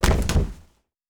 Padding on Glass Intense Hit.wav